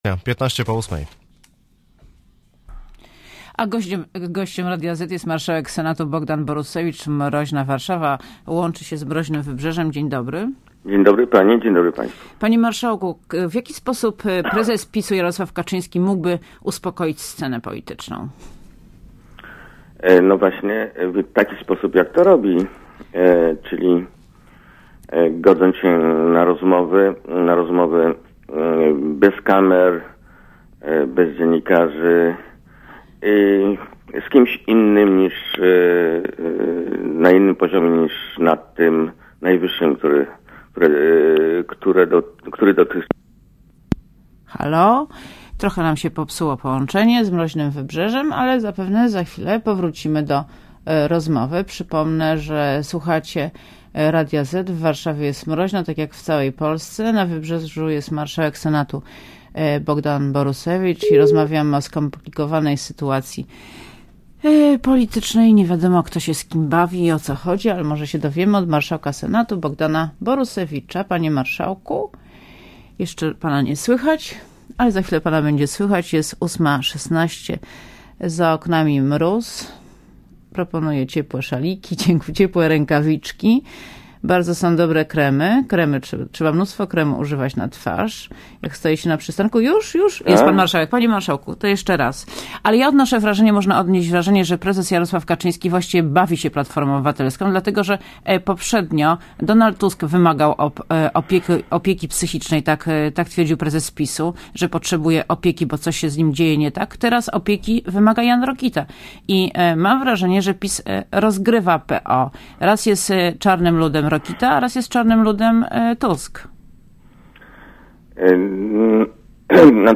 * Posłuchaj wywiadu * Gościem Radia ZET jest marszałek Senatu Bogdan Borusewicz.